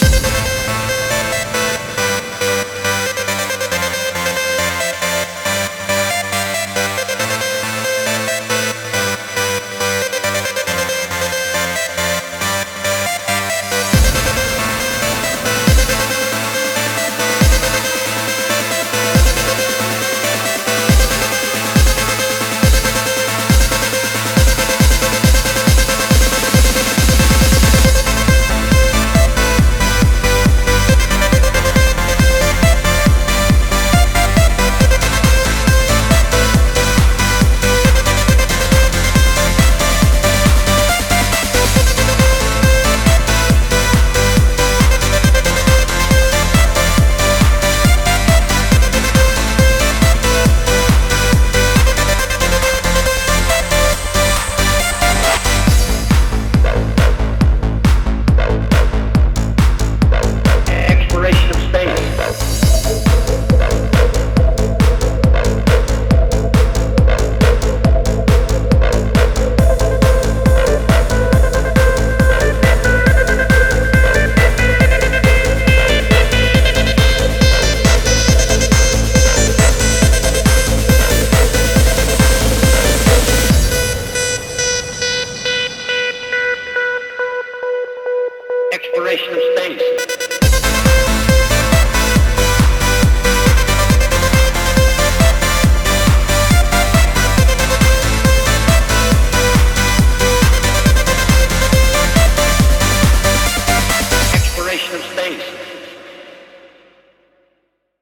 BPM69-138
Audio QualityMusic Cut
banger trance song